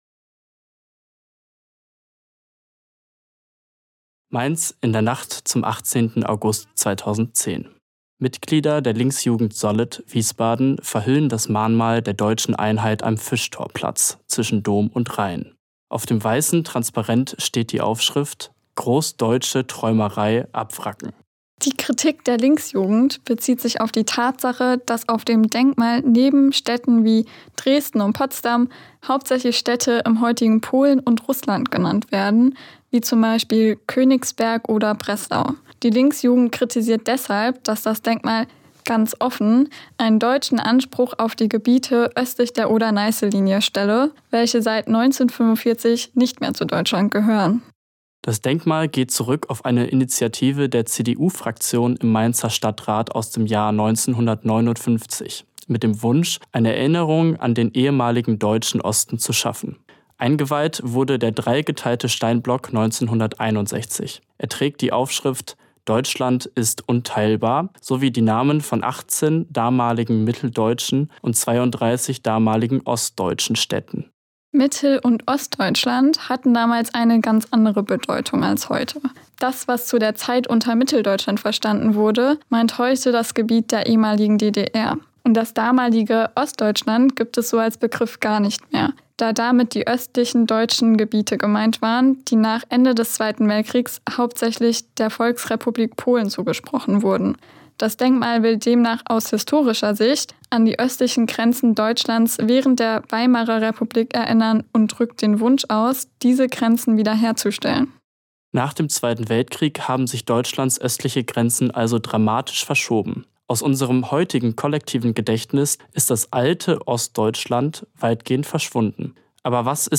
unterhalten wir uns in diesem Podcast